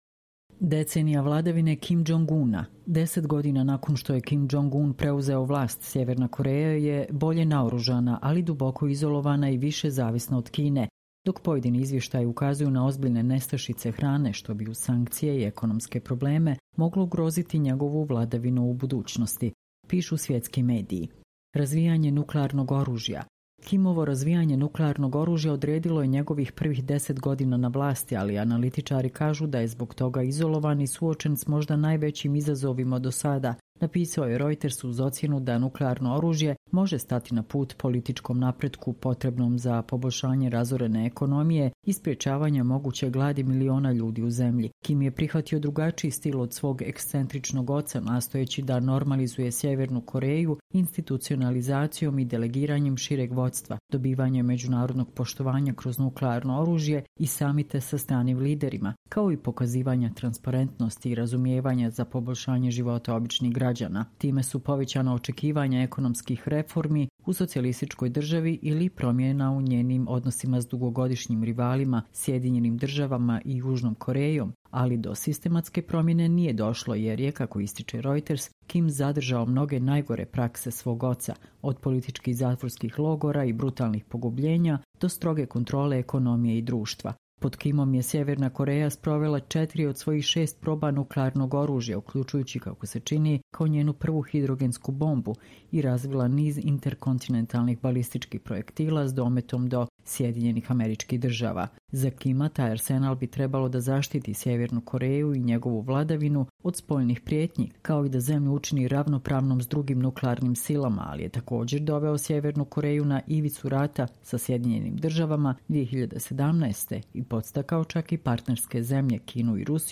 Čitamo vam: Decenija vladavine Kim Džong Una